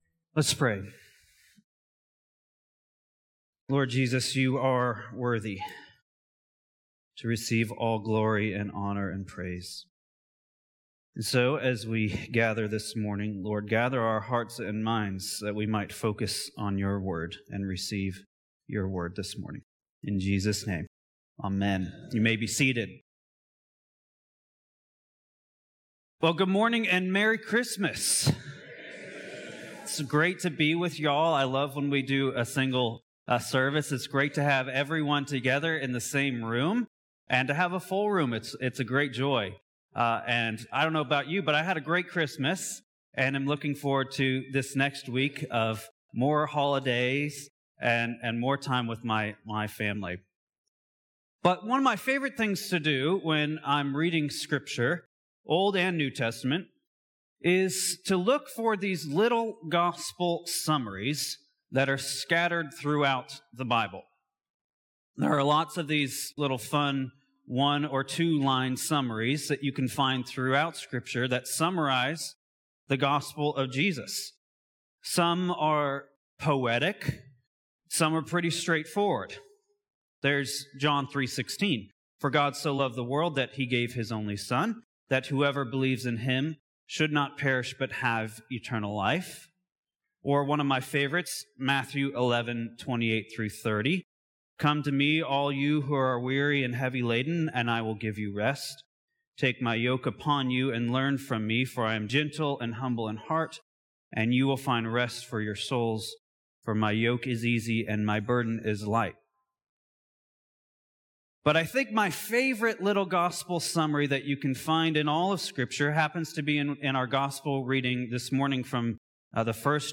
Sermons Join in a worship service!